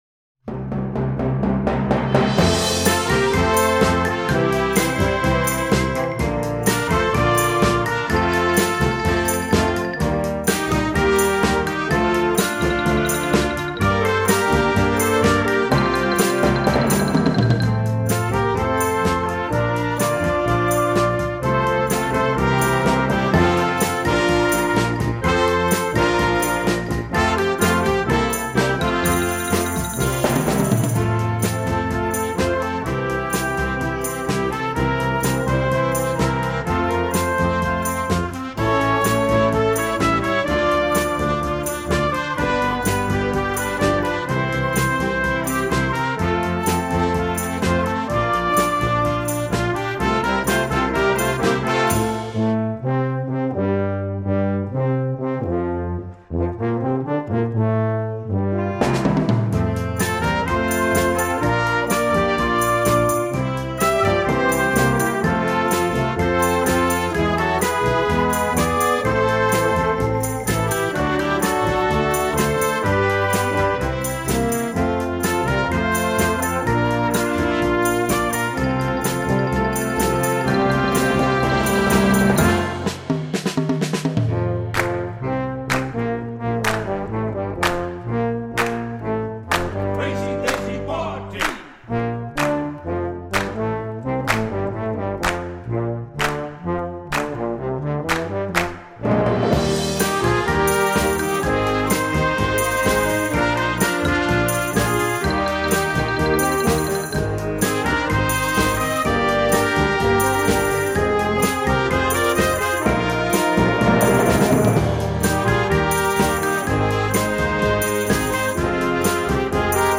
Gattung: für Jugendblasorchester (Flex 5 + Schlagwerk/Perc)
Besetzung: Blasorchester
Besetzung: Flex 5 + Schlagwerk/Percussion